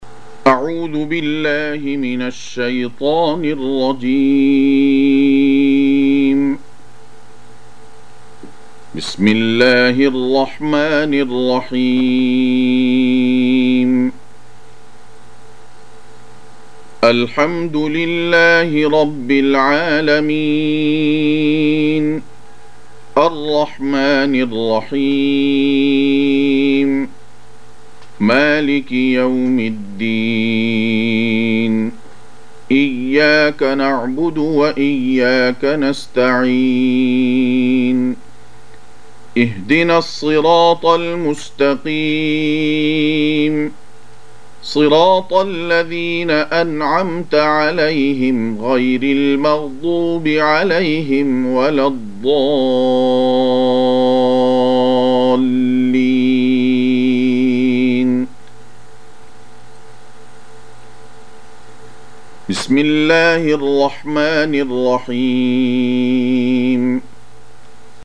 1. Surah Al-F�tihah سورة الفاتحة Audio Quran Tarteel Recitation
Surah Repeating تكرار السورة Download Surah حمّل السورة Reciting Murattalah Audio for 1. Surah Al-F�tihah سورة الفاتحة N.B *Surah Includes Al-Basmalah Reciters Sequents تتابع التلاوات Reciters Repeats تكرار التلاوات